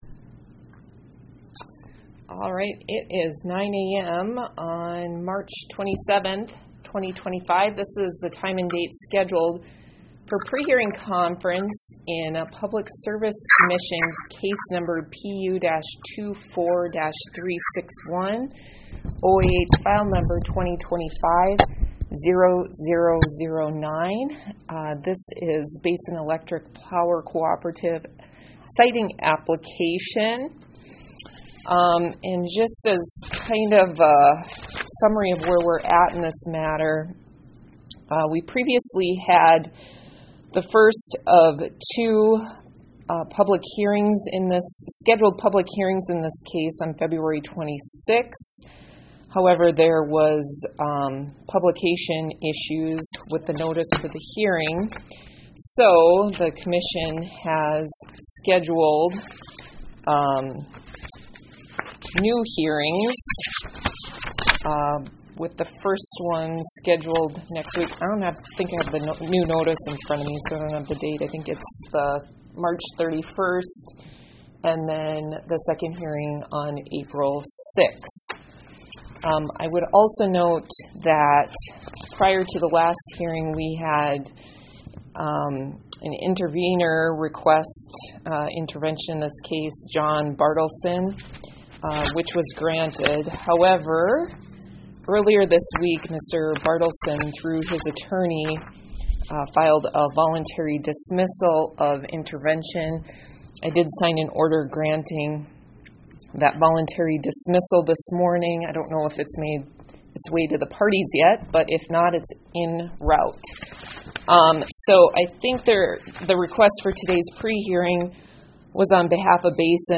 Electronic Recording of 27 March 2025 Prehearing Conference